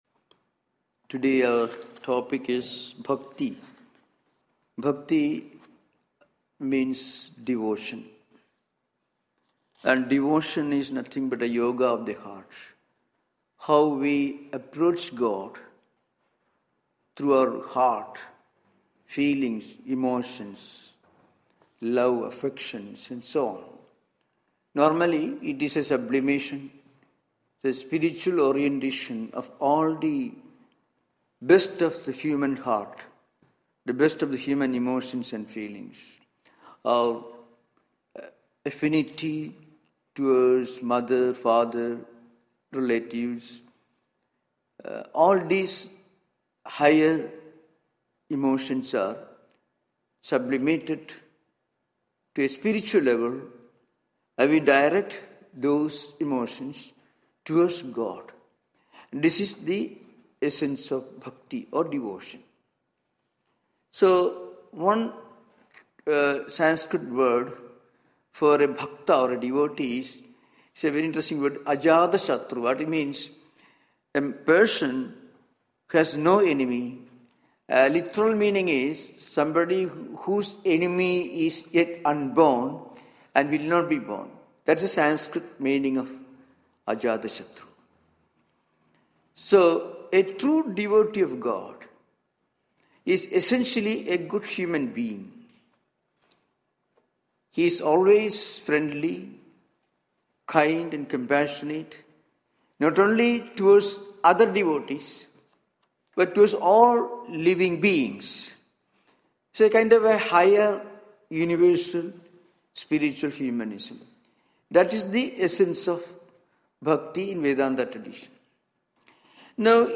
Sunday Lectures